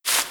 SandStep2.wav